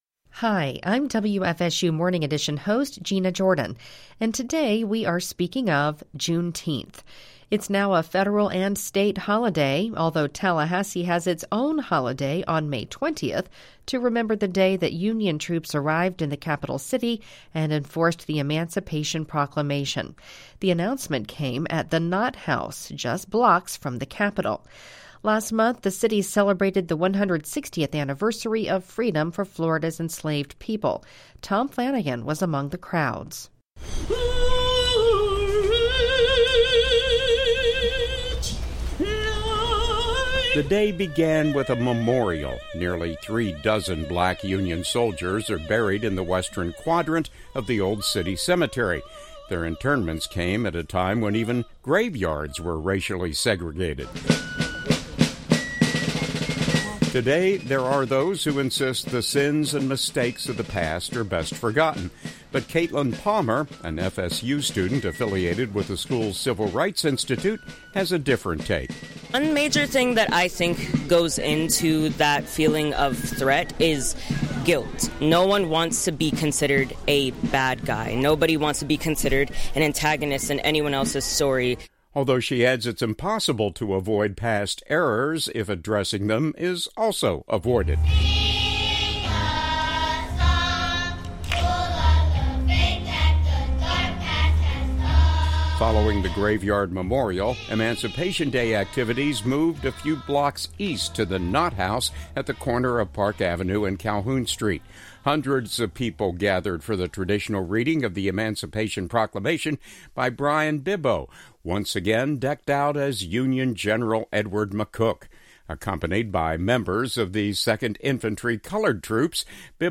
Today on the Speaking Of Radio Hour , we’re getting a history lesson and some analysis from two local historians and scholars.